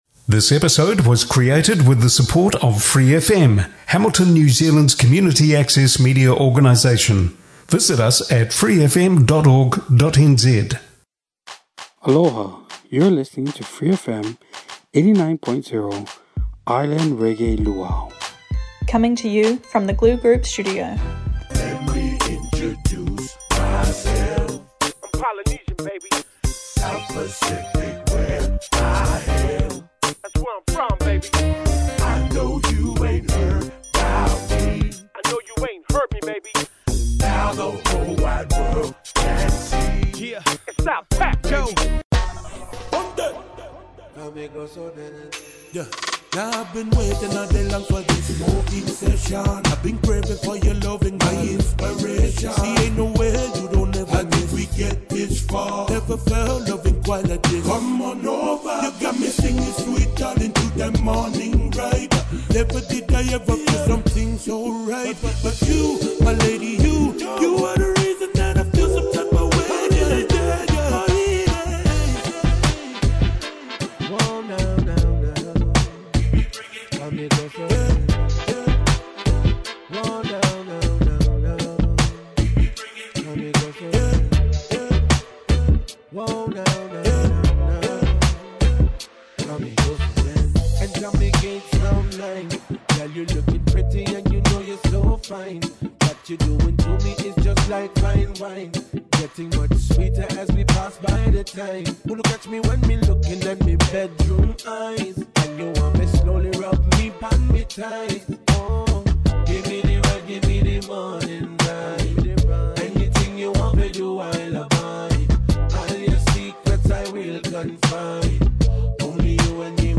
A feast of reggae, delivered in uniquely Hawaiian style.